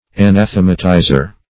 anathematizer - definition of anathematizer - synonyms, pronunciation, spelling from Free Dictionary
Search Result for " anathematizer" : The Collaborative International Dictionary of English v.0.48: Anathematizer \A*nath"e*ma*ti`zer\, n. One who pronounces an anathema.